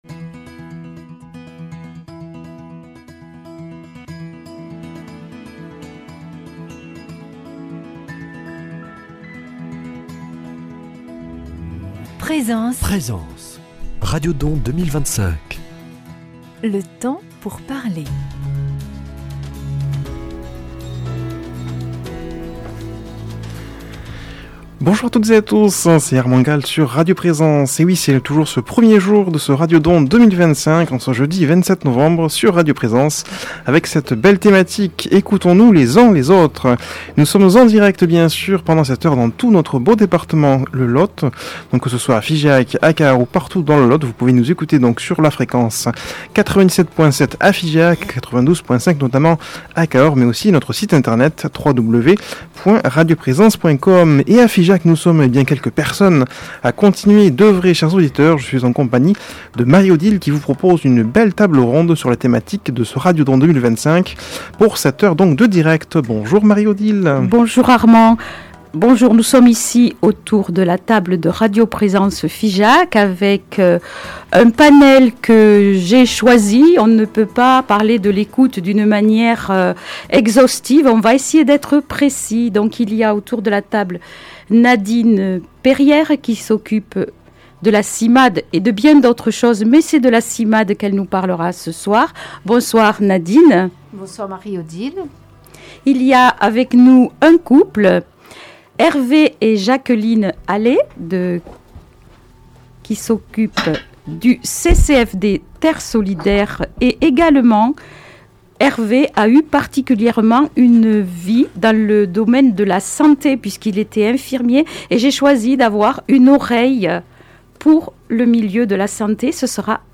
Direct Radio Don du 27 Novembre 17h 18h Figeac